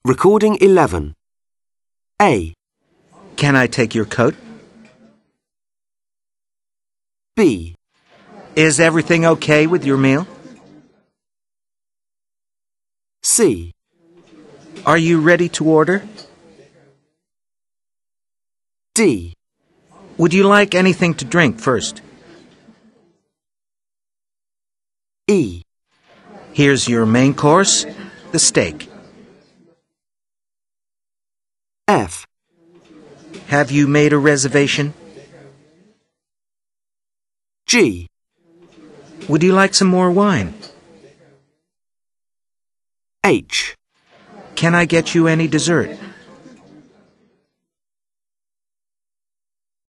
Dialog - direct link ALTERNATIVE LINKS